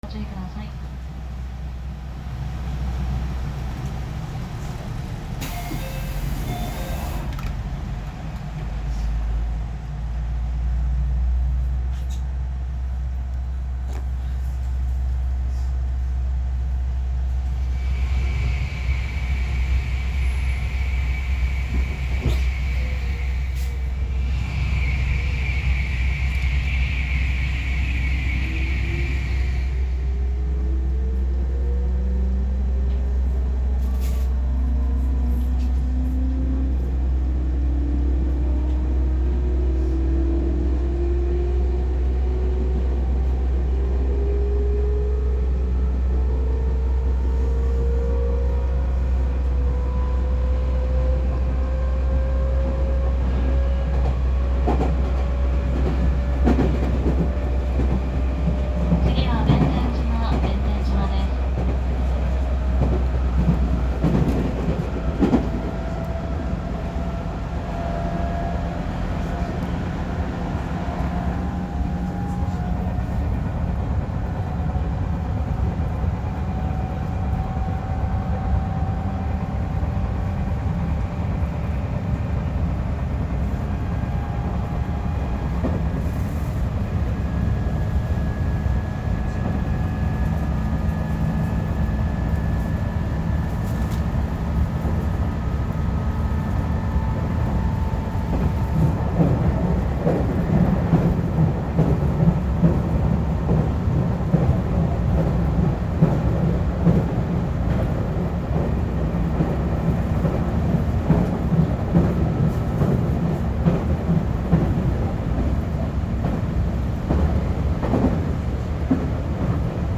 ・311系走行音
【東海道線】新居町→弁天島（3分14秒：5.93MB）
界磁添加励磁制御で、走行音自体は211系と変わりません。
311_Araimachi-Bentenjima.mp3